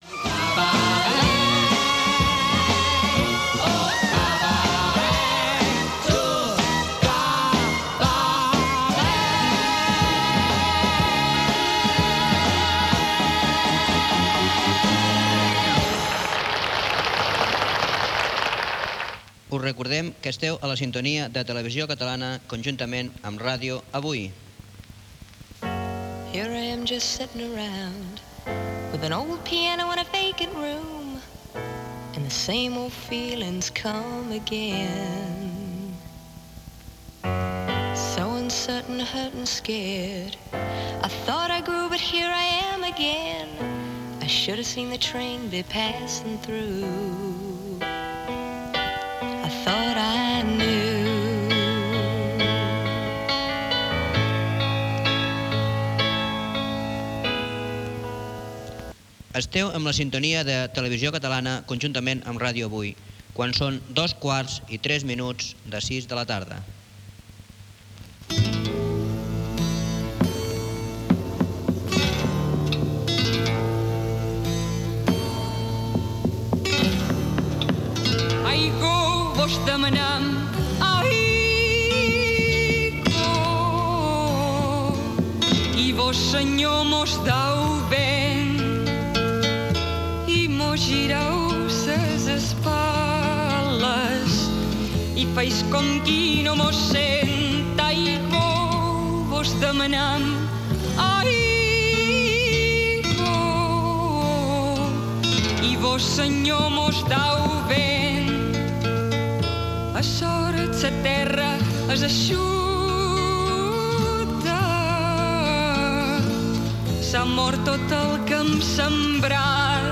Música, identificació, música, identificació i hora, cançó, avís de la transmissió del concert de Sant Esteve des del Palau de la Música, tema musical, identificació i hora.
Musical
FM